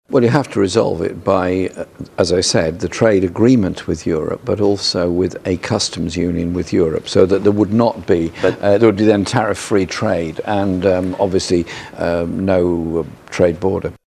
Meanwhile, Speaking on the BBC’s Andrew Marr Show, Jeremy Corbyn insisted there is a way of avoiding a hard border in Ireland: